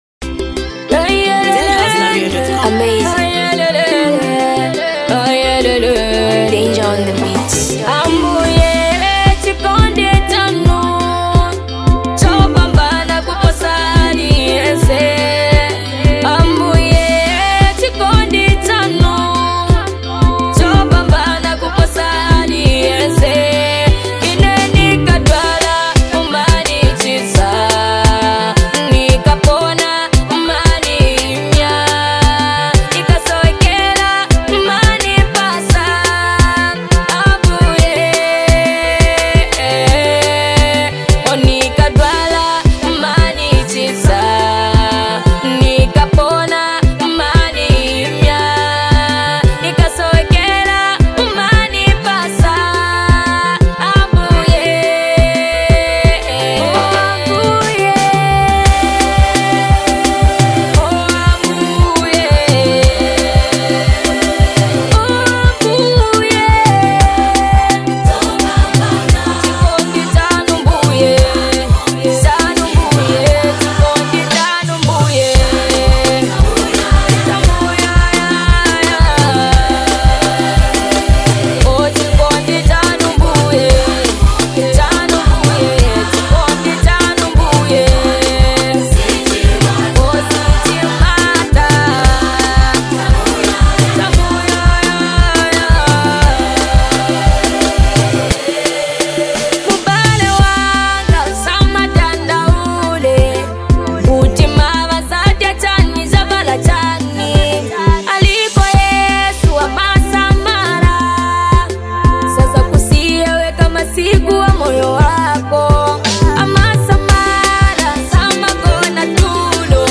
a powerful and soulful track